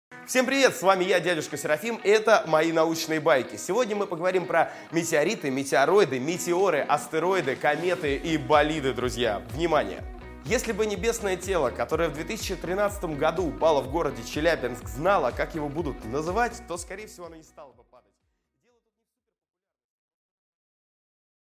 Аудиокнига Космические глыбы | Библиотека аудиокниг